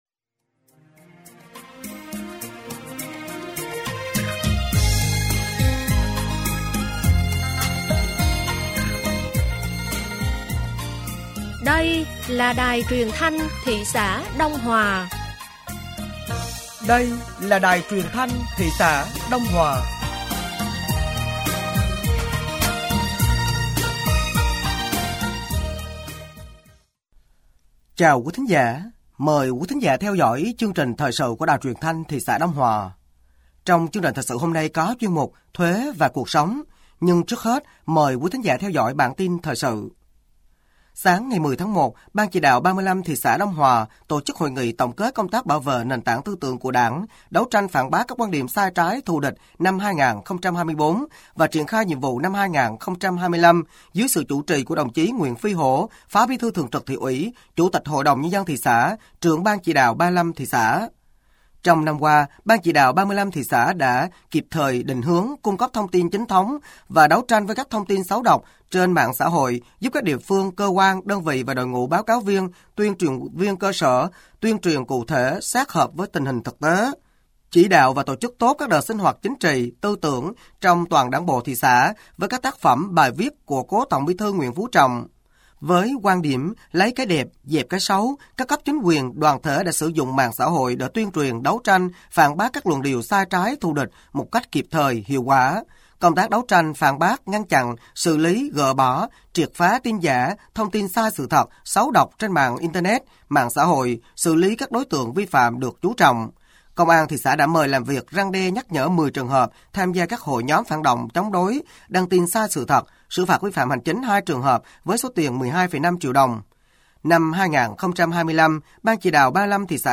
Thời sự tối ngày 10 và sáng ngày 11 tháng 01 năm 2025